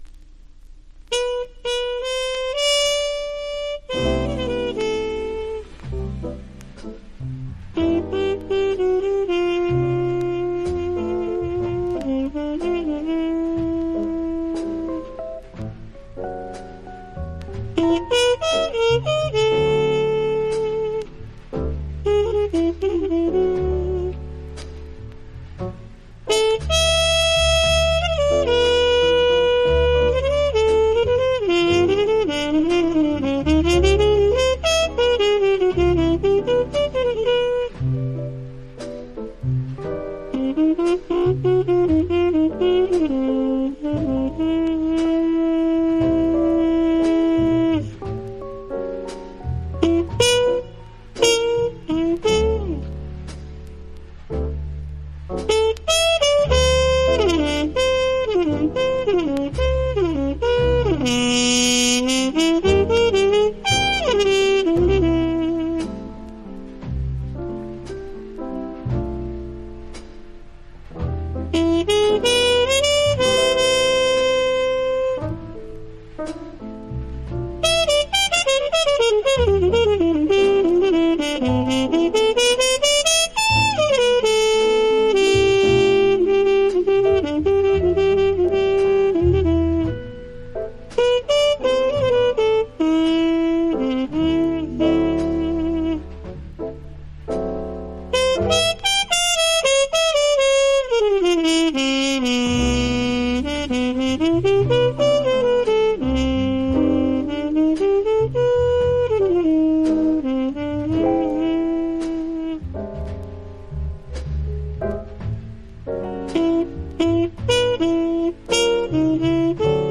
（プレスにより少しチリ、プチ音ある曲あり）
Genre US JAZZ